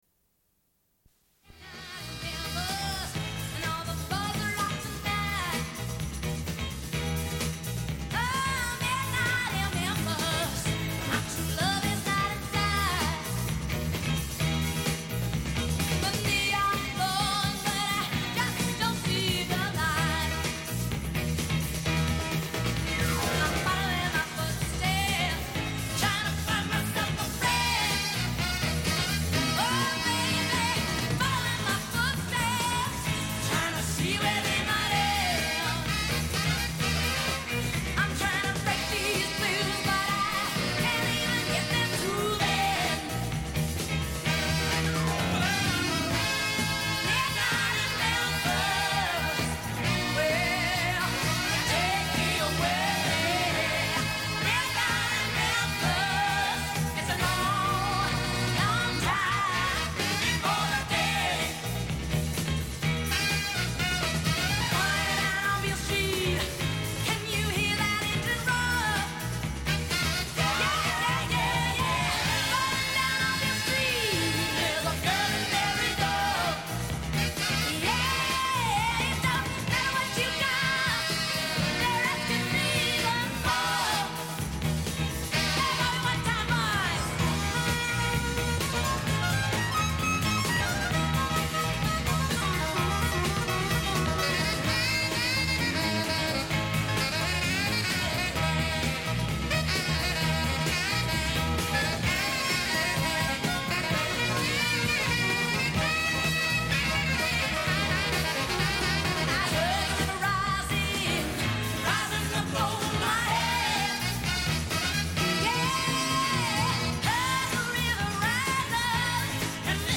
Une cassette audio, face A00:47:05